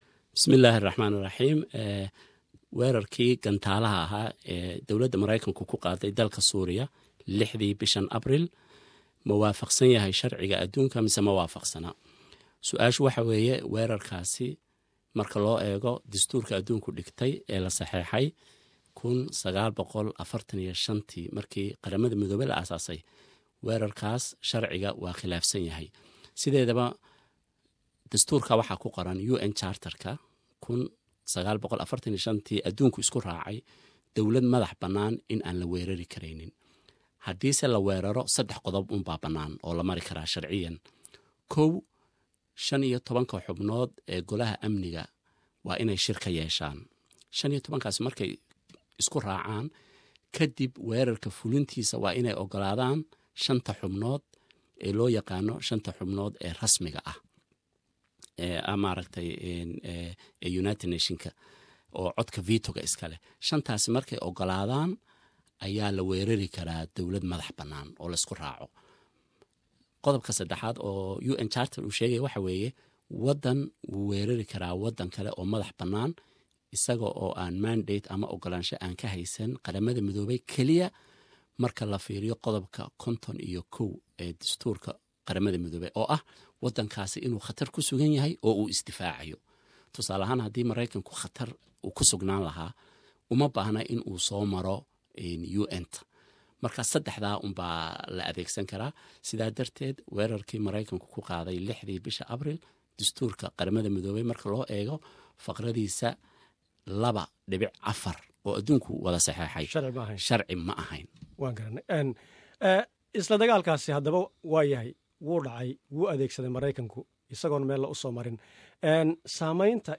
Wareysi